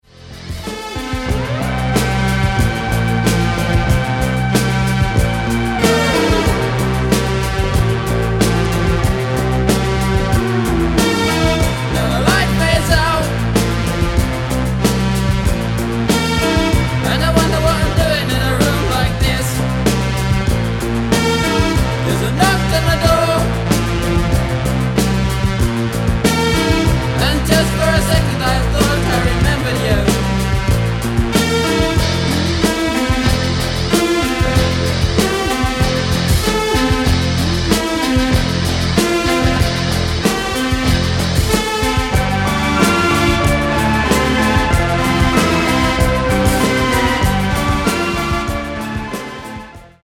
Genere:   Disco | Pop | New Wave